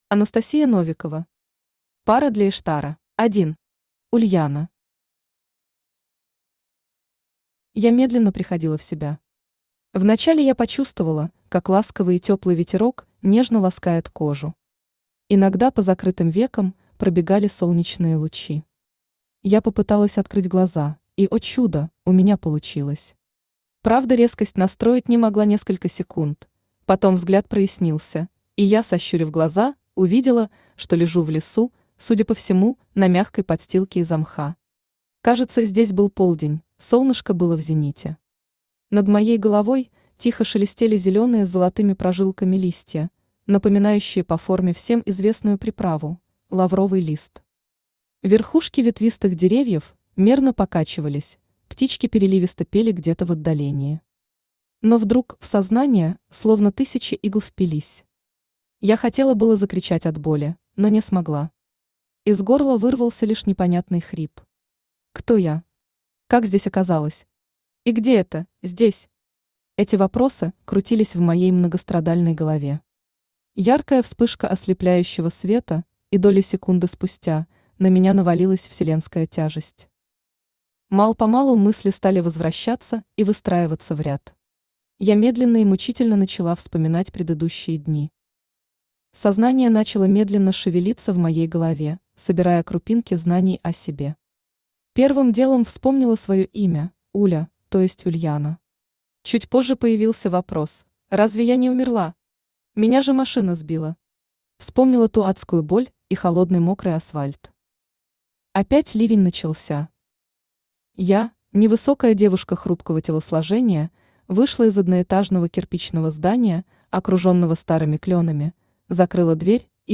Aудиокнига Пара для иштара Автор Анастасия Новикова Читает аудиокнигу Авточтец ЛитРес.